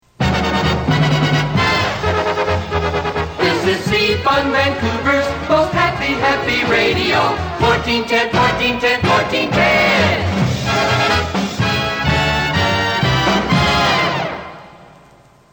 JINGLES FROM EARLY 'FUNLAND' RADIO